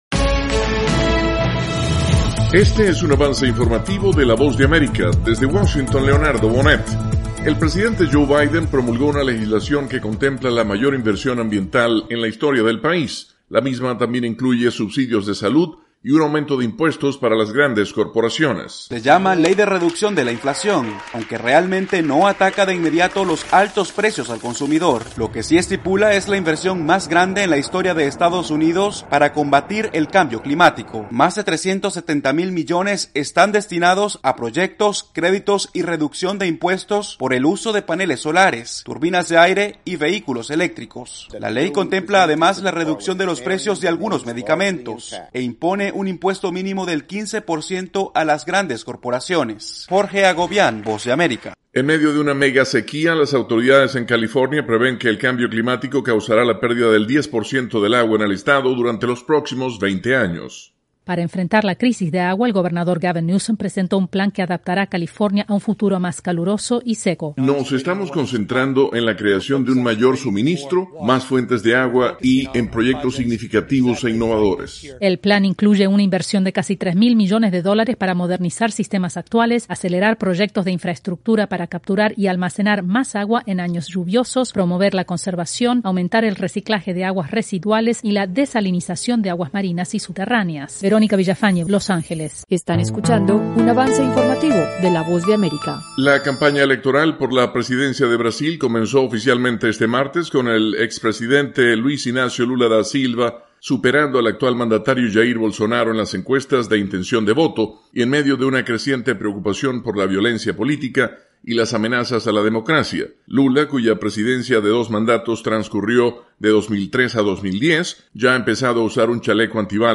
Avance Informativo - 7:00 PM